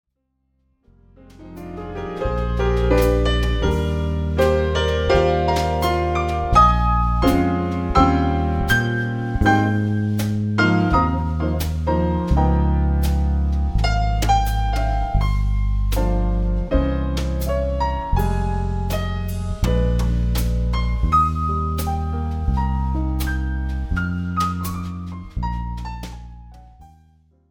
No!Sleep (Jazz)
Die Band spielt in erster Linie gefälligen Swing, Jazzballaden und Bossa, hat aber auch Funk und Rock/Pop im Repertoire.
Combo, Jazzballade.mp3